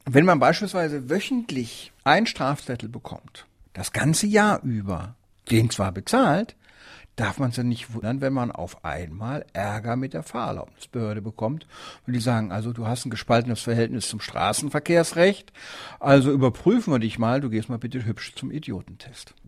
Magazin + O-Ton: Notorischen Falschparkern droht MPU